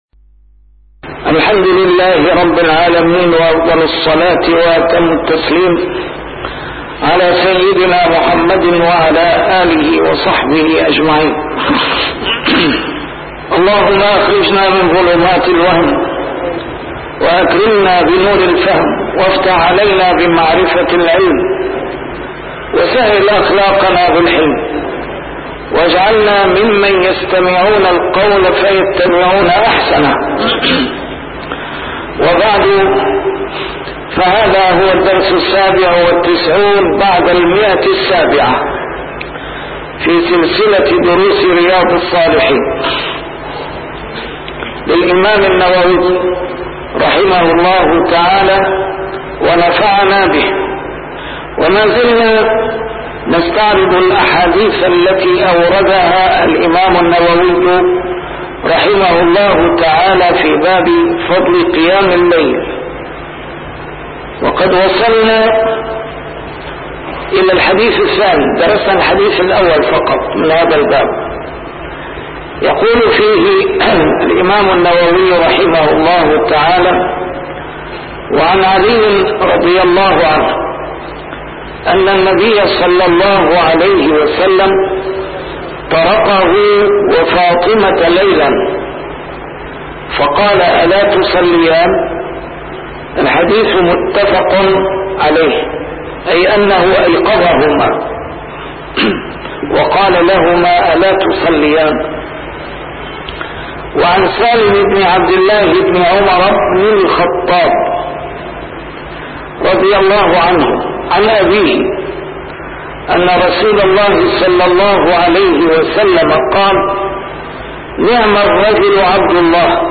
A MARTYR SCHOLAR: IMAM MUHAMMAD SAEED RAMADAN AL-BOUTI - الدروس العلمية - شرح كتاب رياض الصالحين - 797- شرح رياض الصالحين: فضل قيام الليل